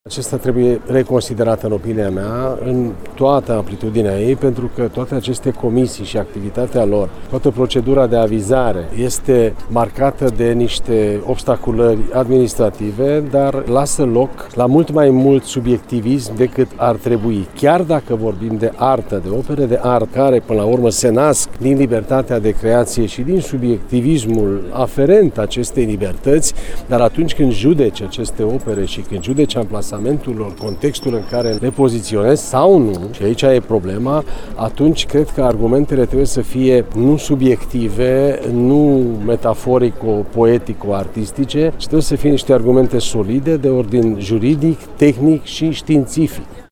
Prezent la Timișoara, ministrul Culturii, Demeter Andras, a declarat că legislaţia actuală asupra patrimoniului trebuie revizuită, deoarece există multe obstacole administrative şi subiectivism în evaluarea bunurilor de patrimoniu.